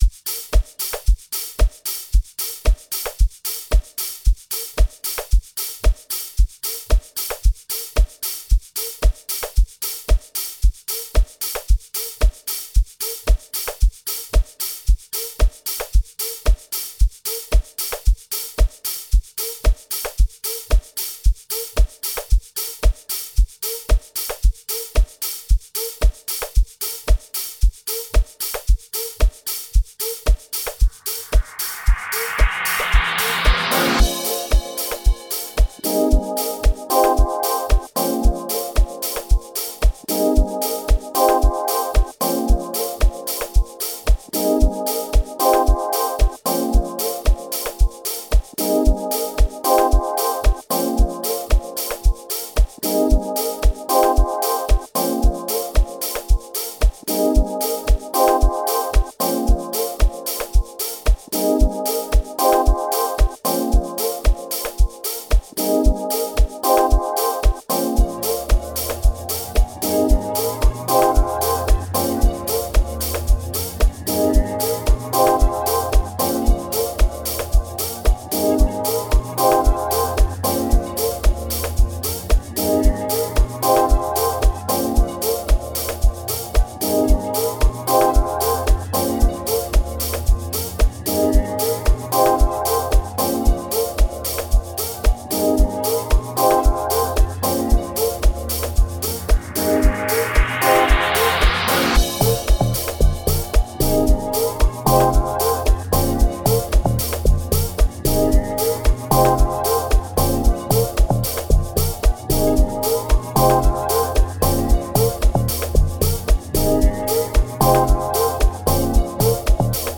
Production mixtape